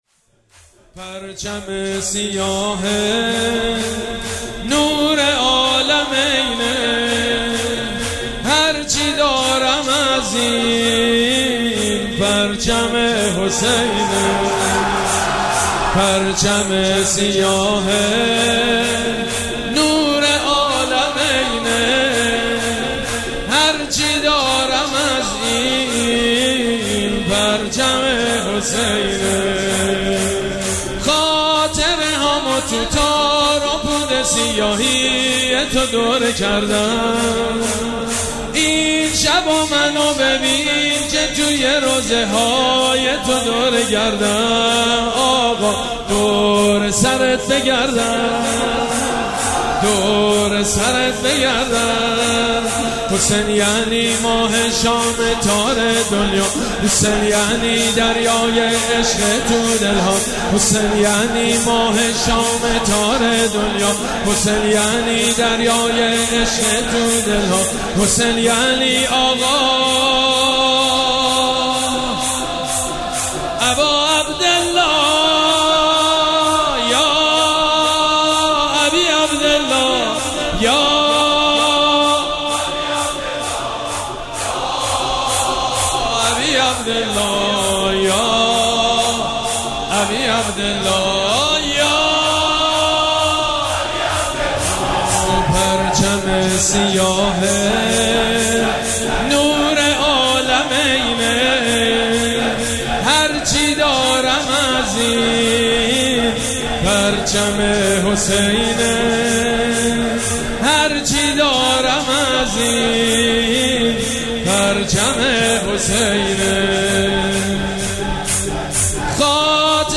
مراسم عزاداری شب دوم محرم الحرام ۱۴۴۷
حسینیه ریحانه الحسین سلام الله علیها
شور
حاج سید مجید بنی فاطمه